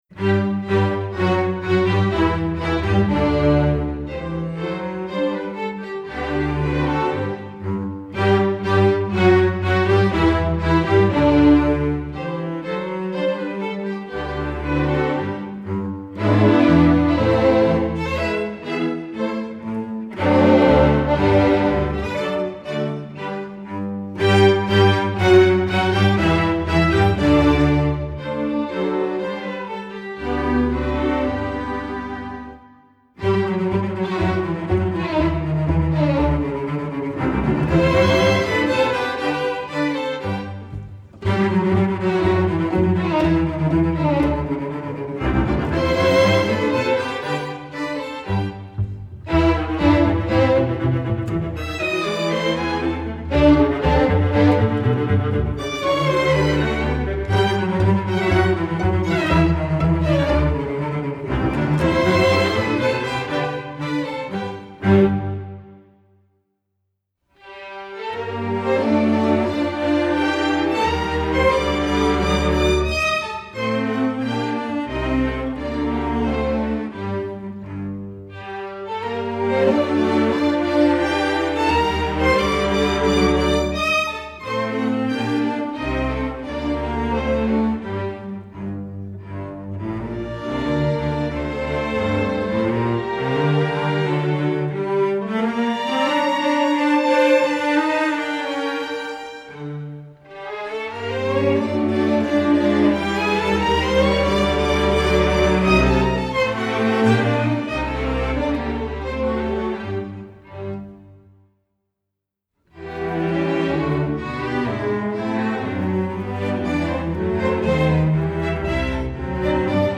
masterwork arrangement